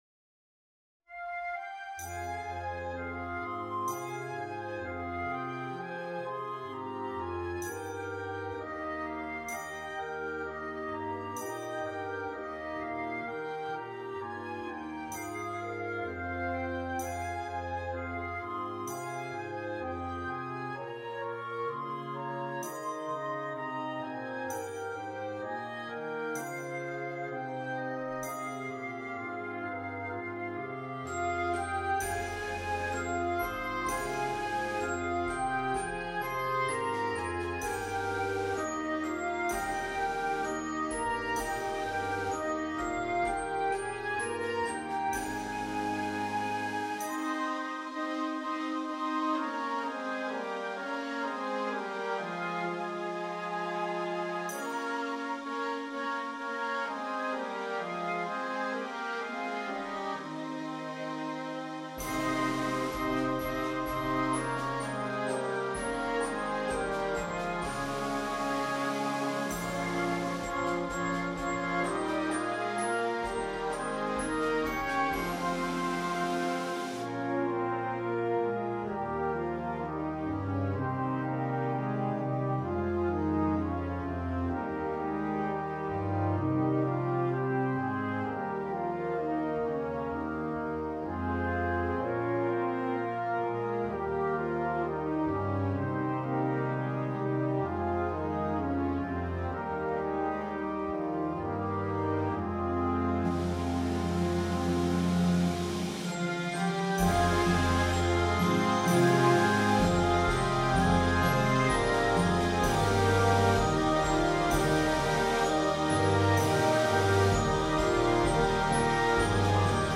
is a slow, contemplative piece.